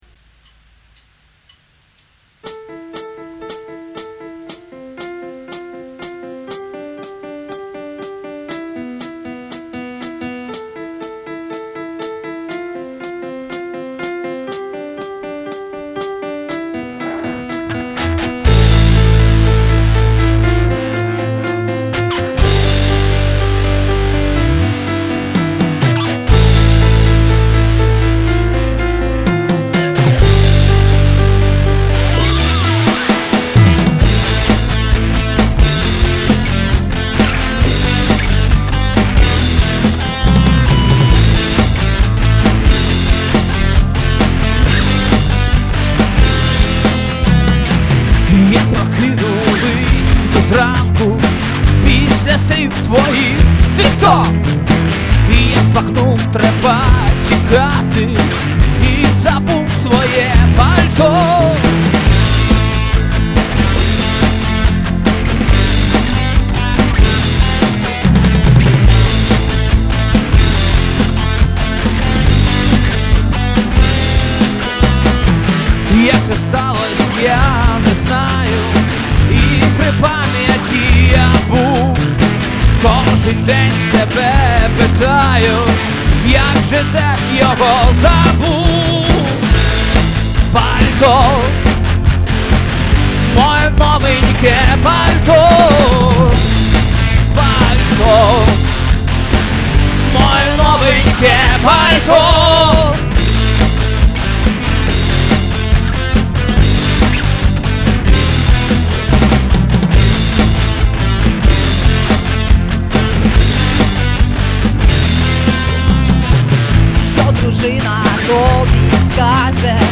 так музика, весела, панк-рок)))))) wink